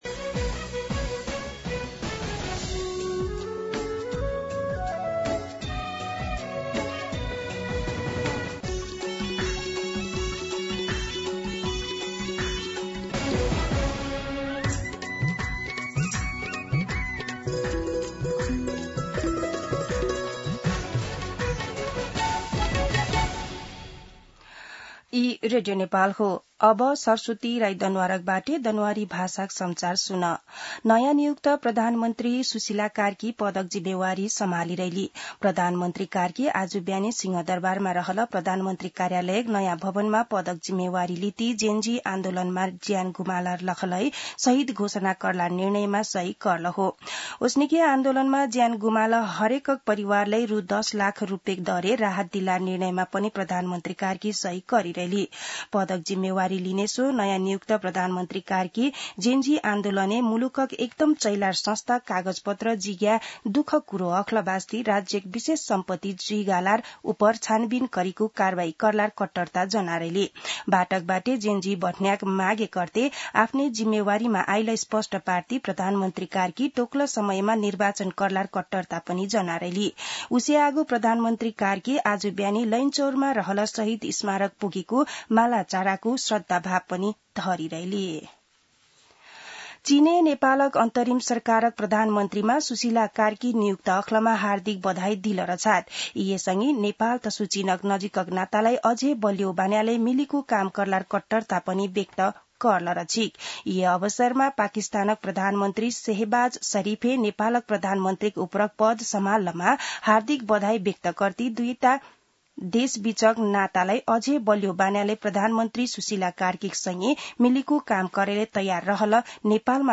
An online outlet of Nepal's national radio broadcaster
दनुवार भाषामा समाचार : २९ भदौ , २०८२